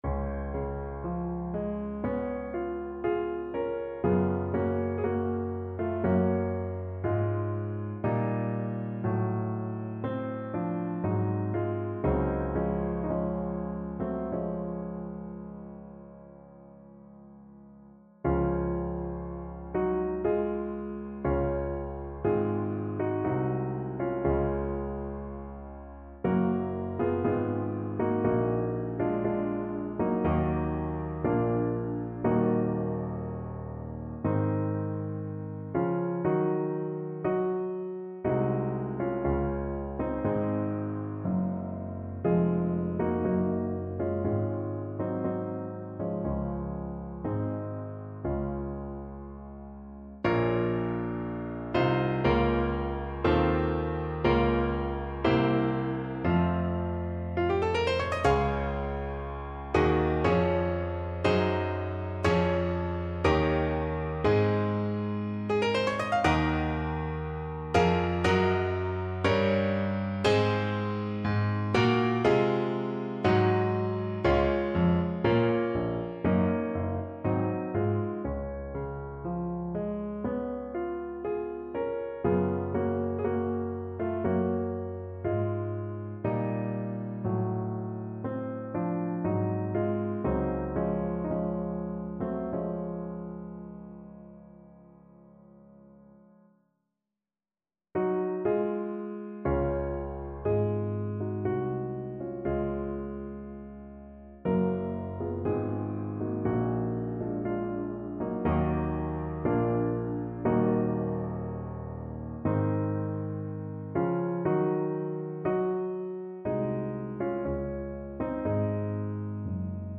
Free Sheet music for Voice
Voice
Db major (Sounding Pitch) (View more Db major Music for Voice )
4/4 (View more 4/4 Music)
Adagio religioso
Db5-F#6
Traditional (View more Traditional Voice Music)